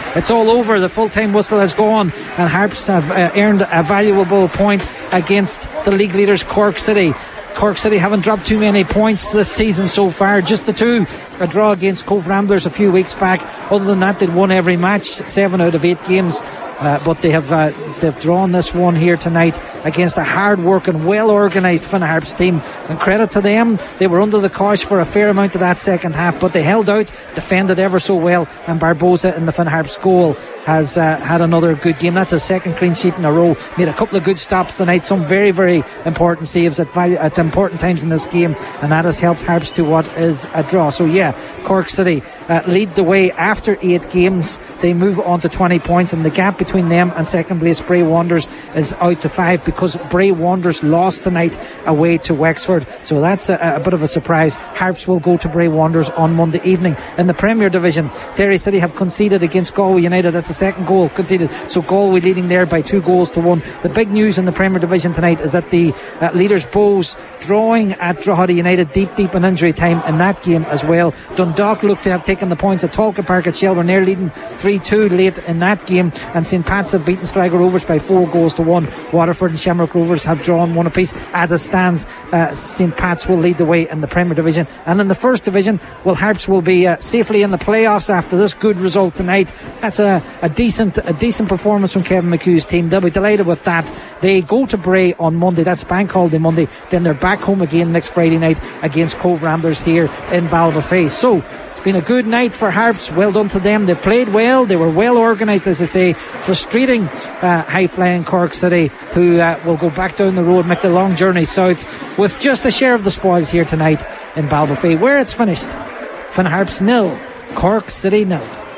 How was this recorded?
was live at full time for Highland Radio Sport…